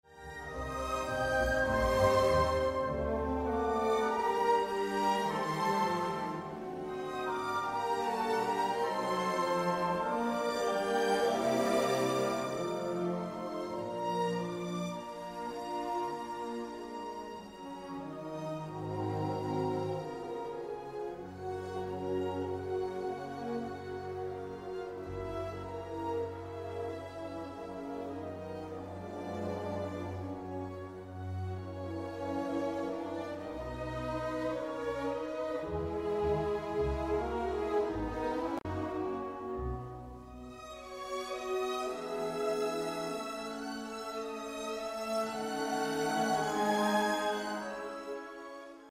For example, just before the end of the development Coleridge-Taylor is in B minor and, from here, shifts quickly onto the dominant and back to the home tonic of C.